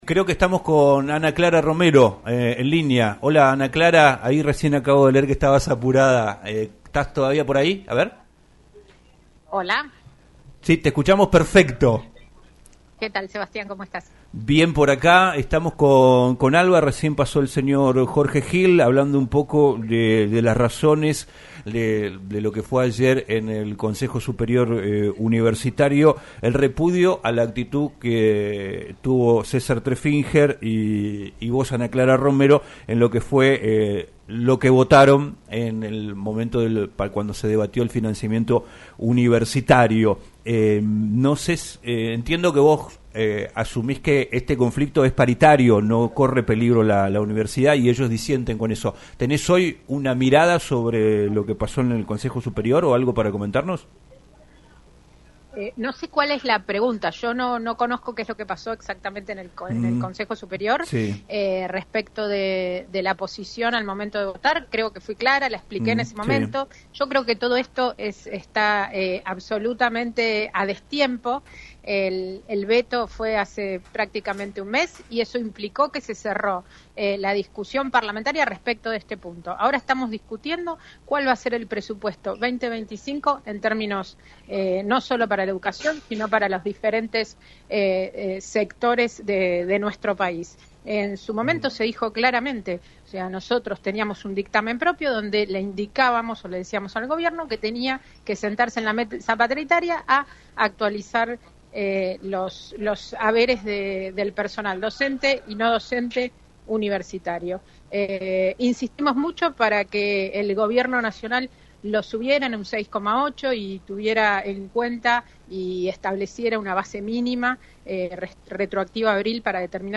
Ana Clara Romero, diputada nacional por el PRO, habló en "Un Millón de Guanacos" por LaCienPuntoUno, sobre el repudio que recibió junto a su par César Treffinger por parte del Consejo Superior de la Universidad Nacional de la Patagonia San Juan Bosco de Comodoro Rivadavia por apoyar el veto del presidente Javier Milei a la Ley de Financiamiento Universitario.